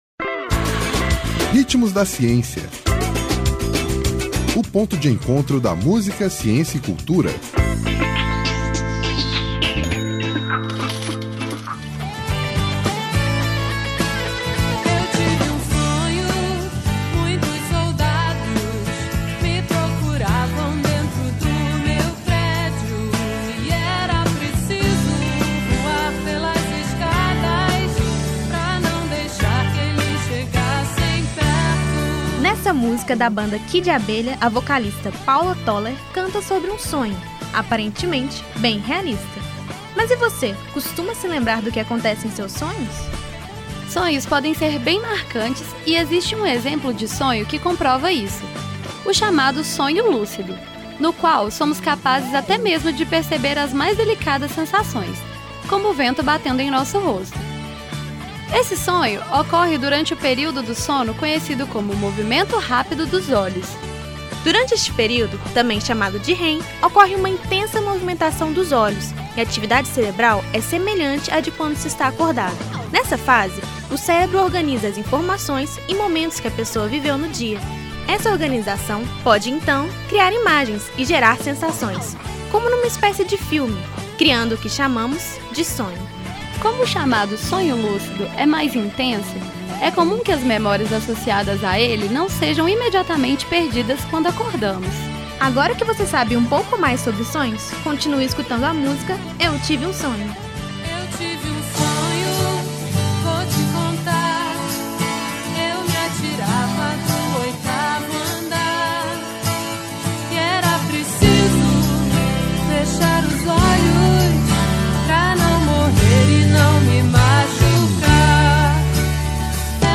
Nesta música da banda Kid Abelha, a vocalista, Paula Toller, canta sobre um sonho, aparentemente, bem realista. Mas e você, costuma se lembrar do que acontece em seus sonhos...Ouça todo o programa: